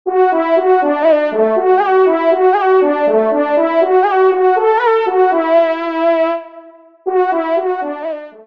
FANFARE
Localisation : Bourgogne (Vienne)